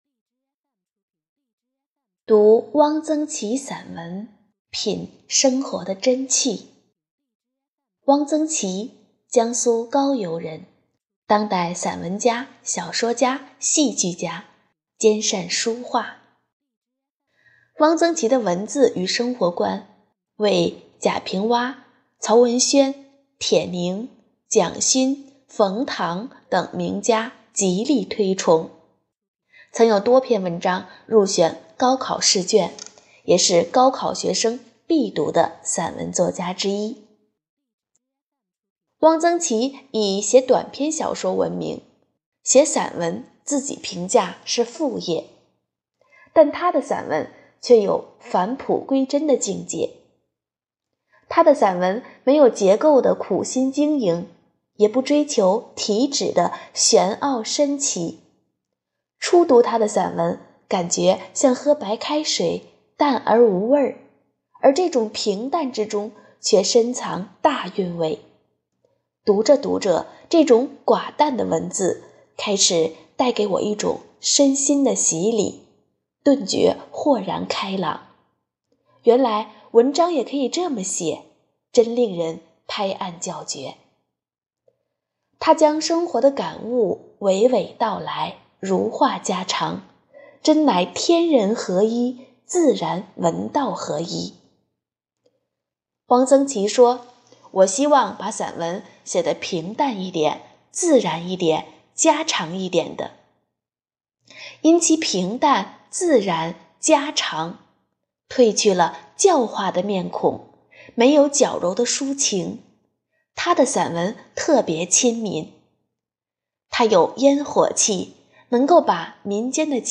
【讲座】丰图讲座 | 大师的生活智慧与处世哲学——汪曾祺散文阅读专题讲座
活动地点：丰南图书馆 线上活动